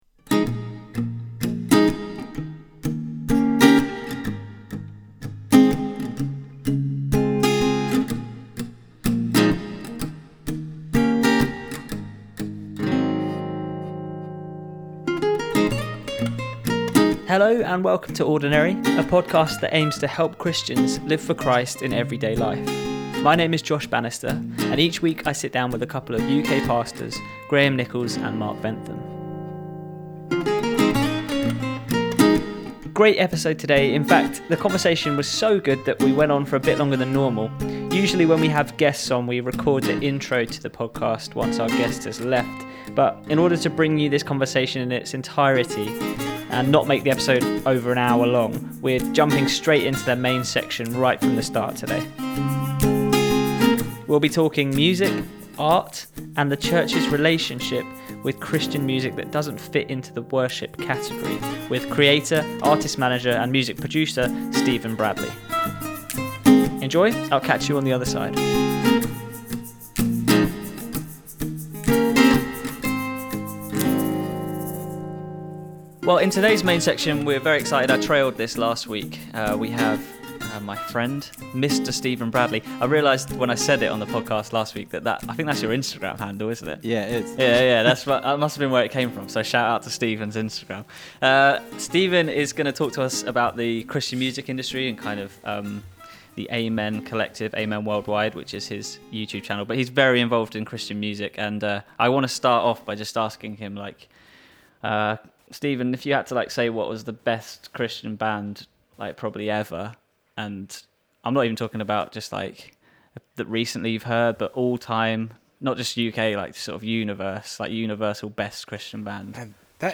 In fact the conversation was so good today that we went on for a bit longer than normal. Usually when we have guests on we record the intro to the podcast once the guest has left but in order to bring you this conversation in it’s entirety and not make the episode over an hour long we’re jumping straight into the main section, right from the start today.